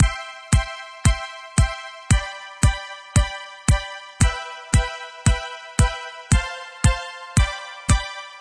[パターン１]：表拍・・・
トラック１に *Signal Follower Device を挿入し、トラック２のボリューム・フェーダーにサイドチェインを設定して、 キックドラムが鳴ったと同時にシンセ音も鳴るようにしました。
Side-chain-test-1.mp3